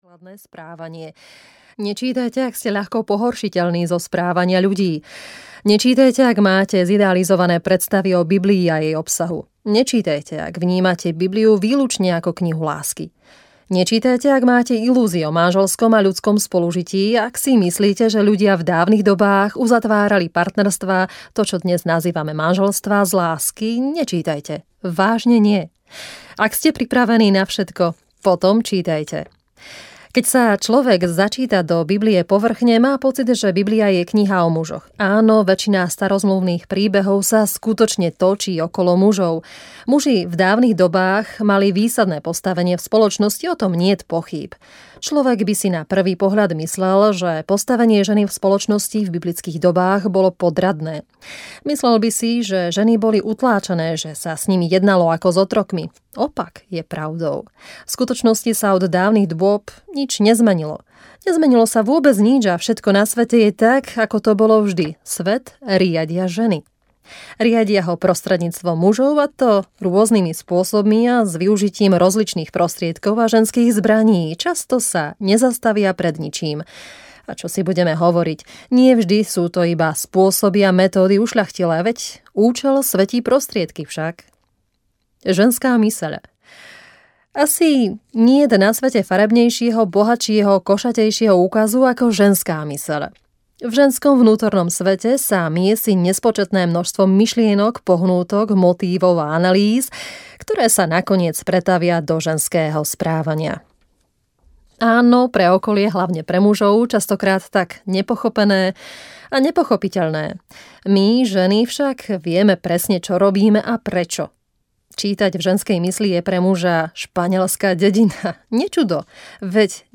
Ženy z divokej záhrady audiokniha
Ukázka z knihy